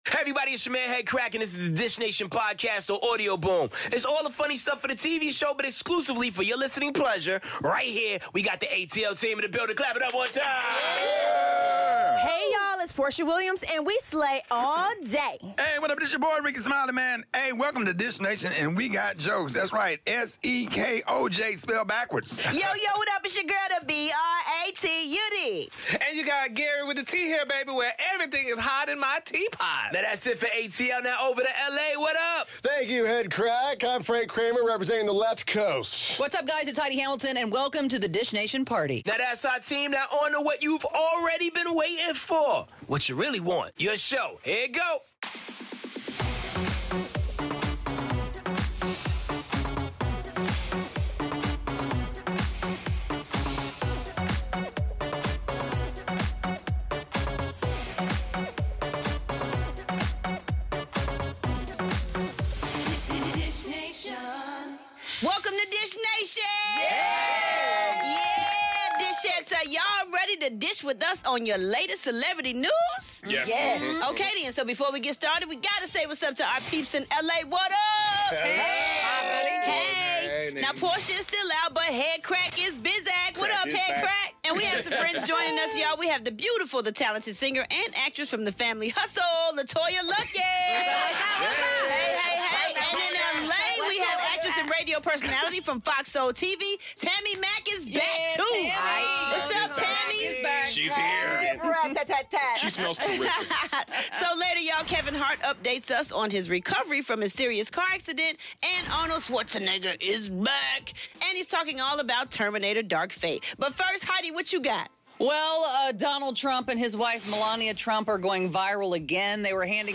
in studio today so tune in!